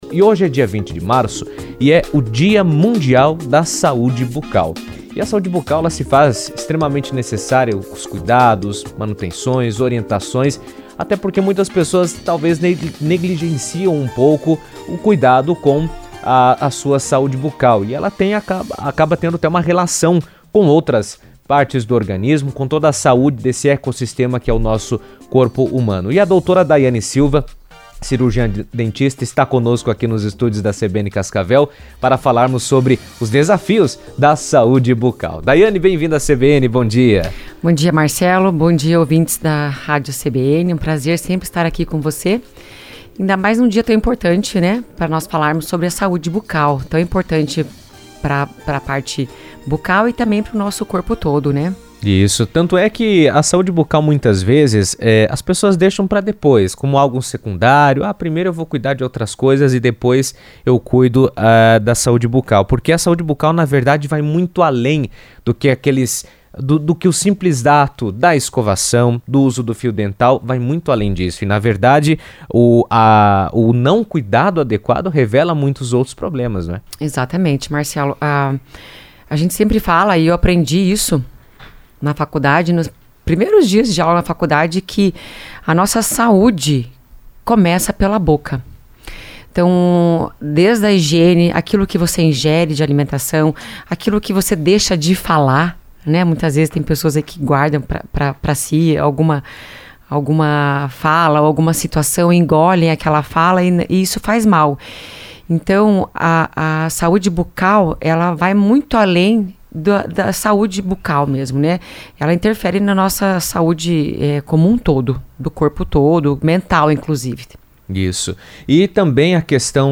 No Dia Mundial da Saúde Bucal, a relação entre a saúde bucal e a saúde do corpo foi tema de entrevista na CBN.